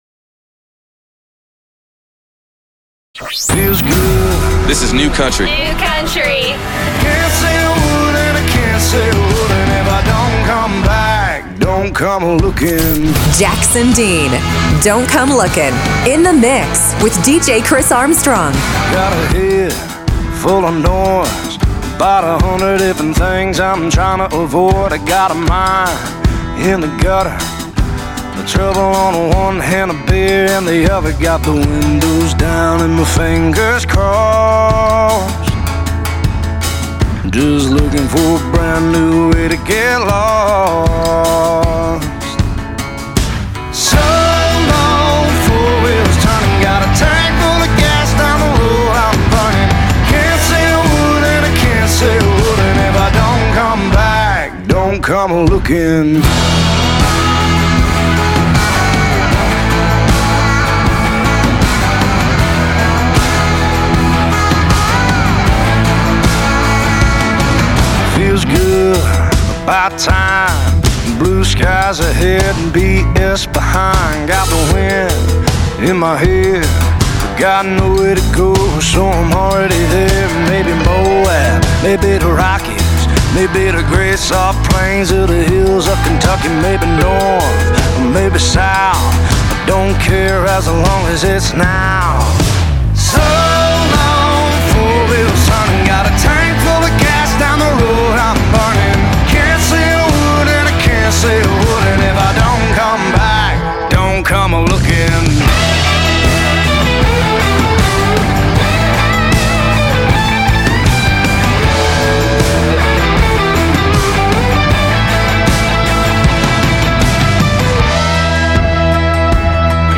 If you missed my slot on Countryline, you can listen here to the original ad-free submission at the link here